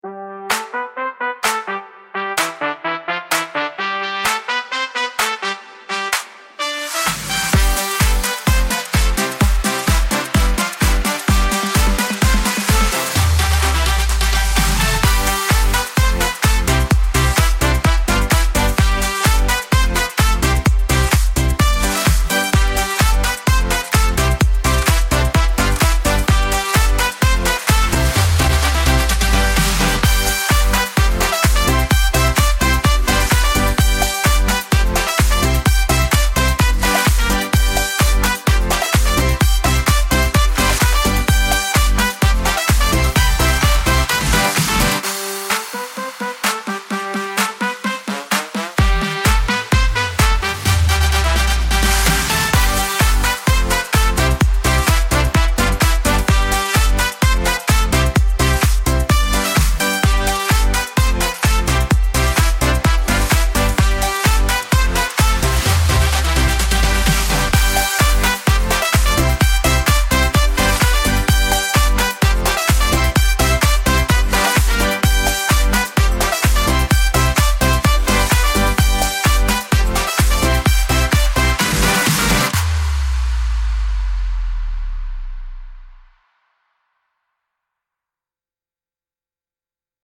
energetic pop instrumental with festive horns and handclaps